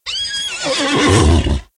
PixelPerfectionCE/assets/minecraft/sounds/mob/horse/idle3.ogg at mc116